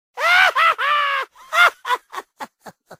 world-of-warcraft-gnome-laugh_26651.mp3